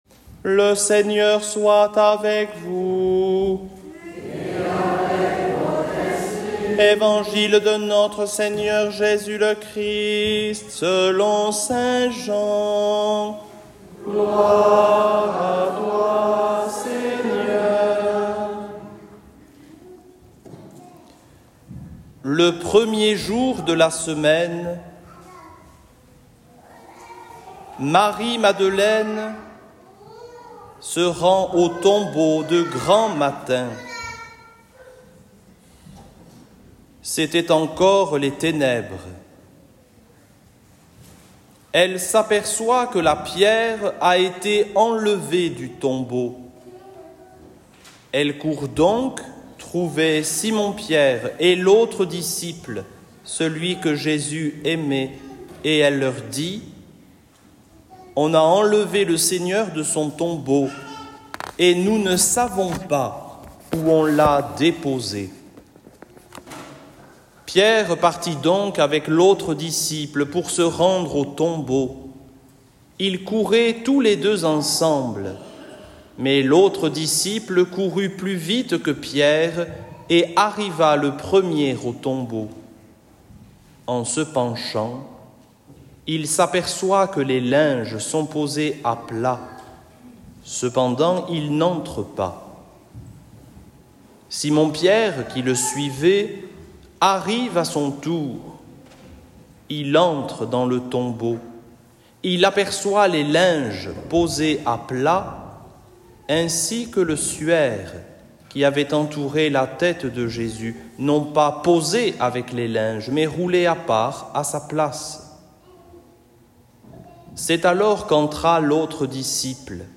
evangile-jour-de-paques.mp3